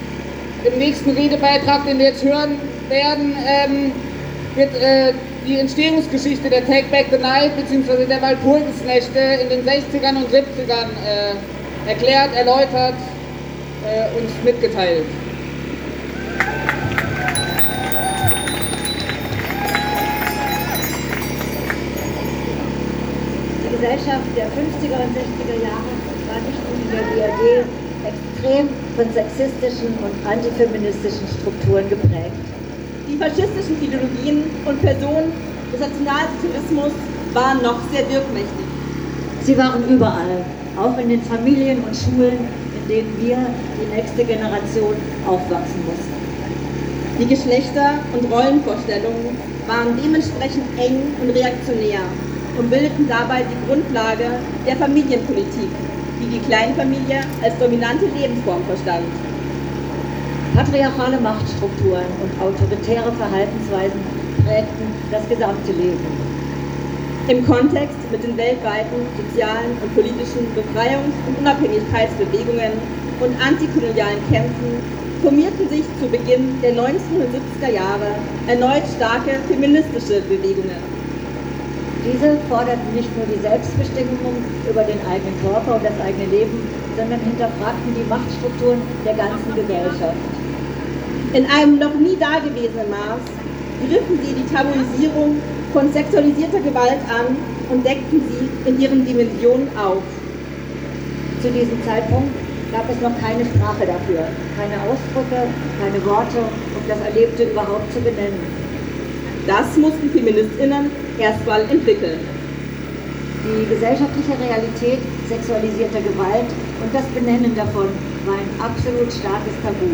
Vor dem Martinstor, am Freiburger Stadtgarten, am Lederleplatz im Stühlinger sowie nahe der Wilhelmstraße wurden Reden gehalten: